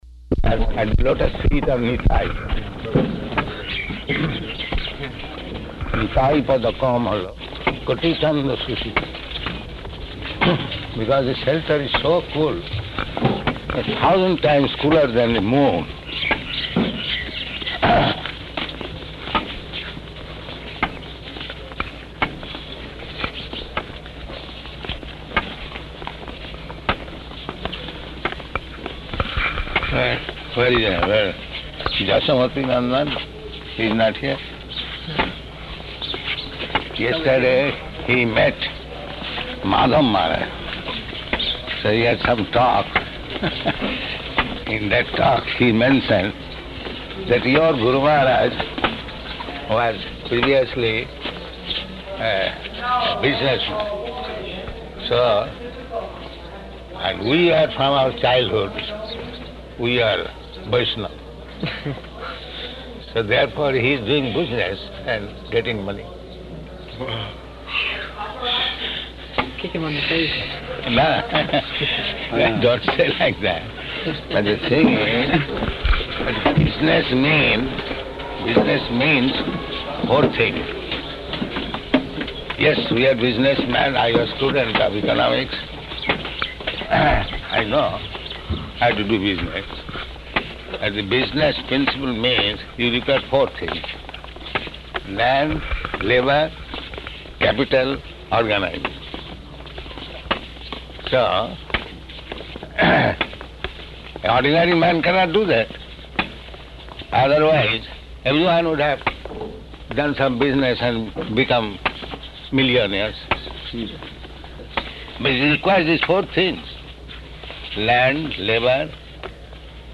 Morning Walk --:-- --:-- Type: Walk Dated: March 11th 1976 Location: Māyāpur Audio file: 760311MW.MAY.mp3 Prabhupāda: ...at the lotus feet of Nitāi.